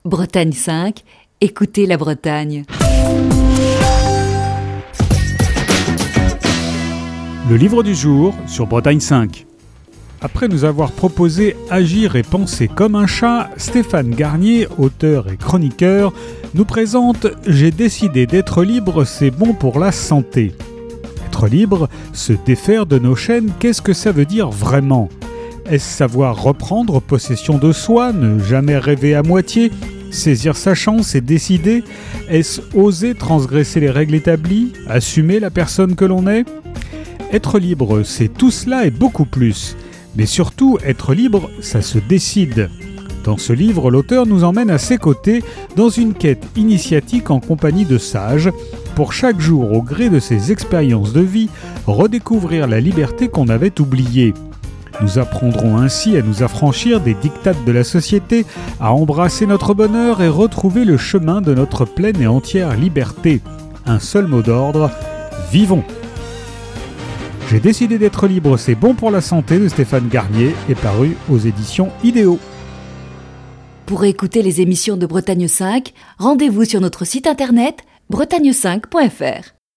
Chronique du 1er décembre 2020.